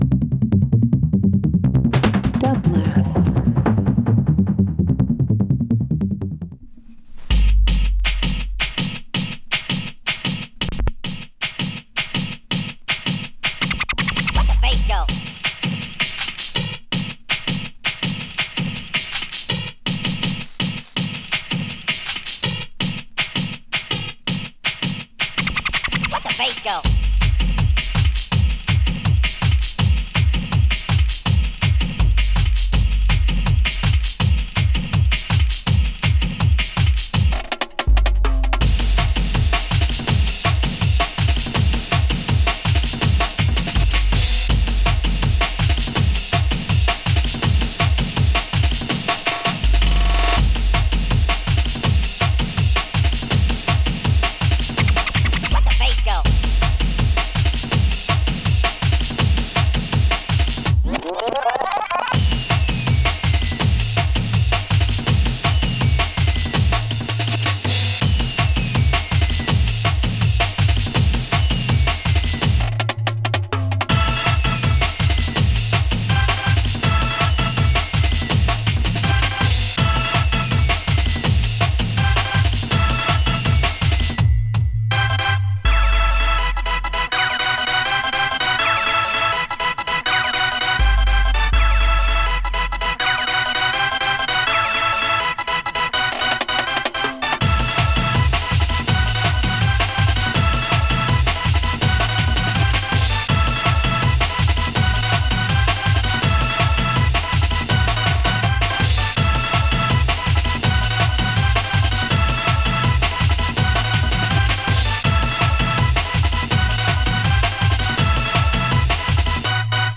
Breaks Dub Electronic Juke